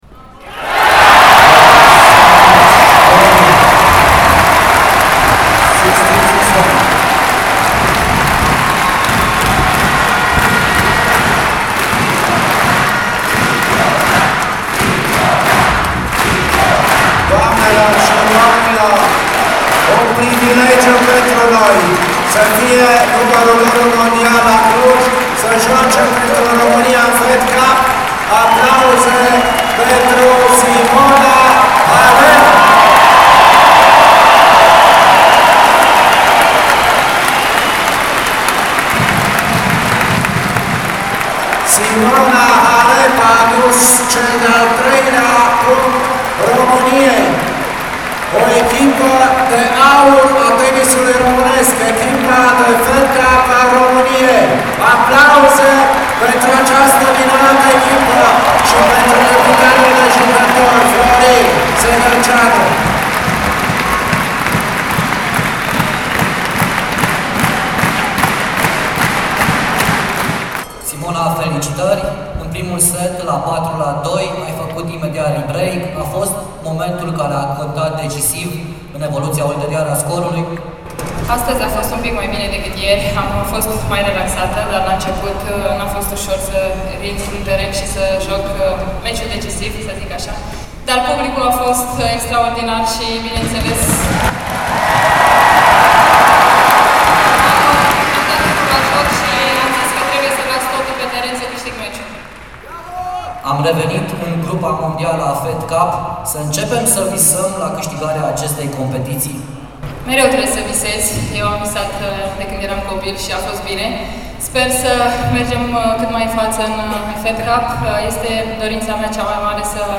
ACTUALIZARE 13.35 Sala s-a umplut din nou cu peste 9.000 de spectatori, iar atmosfera a fost foarte caldă la Cluj.
Halep-face-3-0-reportaj-de-atmosfera.mp3